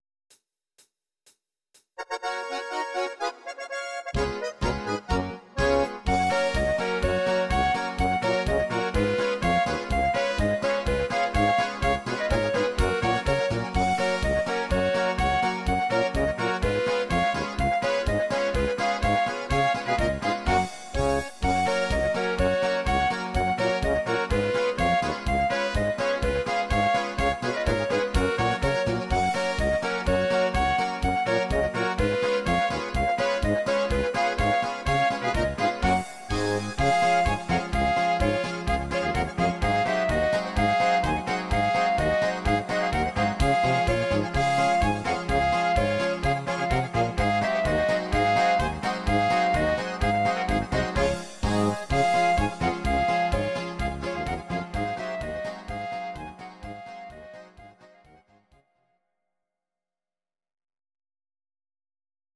Audio Recordings based on Midi-files
Instrumental, Traditional/Folk, Volkstï¿½mlich